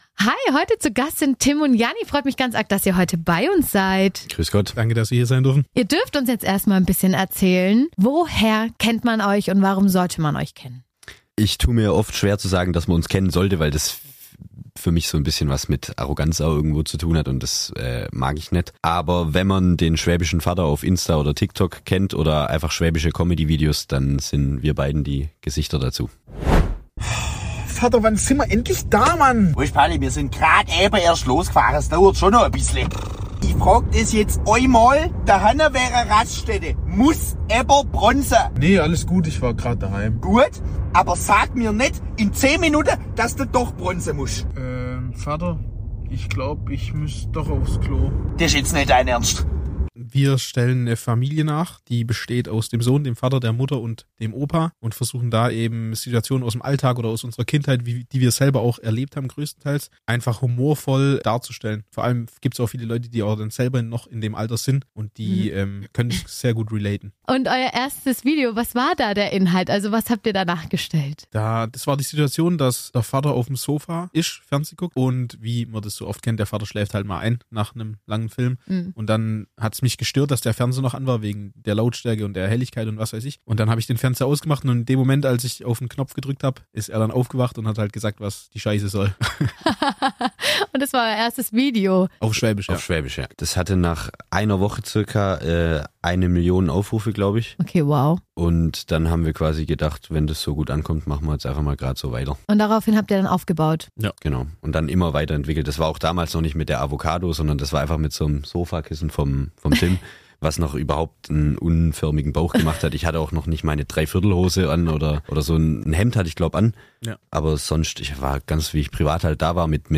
Gemeinsam diskutieren wir, wie sie mit Fame umgehen und warum Authentizität ihr Markenzeichen ist. Natürlich gibt es auch Live-Parodien, Musikrate-Spaß und einen Blick hinter die Kulissen ihrer kreativen Prozesse.